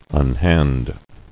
[un·hand]